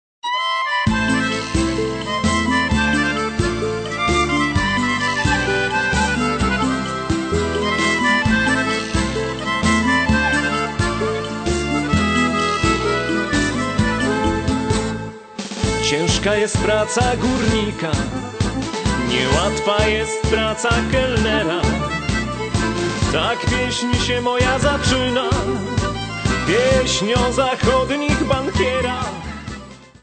3 CD Set of Polish Gypsy Music.